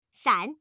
noname / audio / card / female / shan.mp3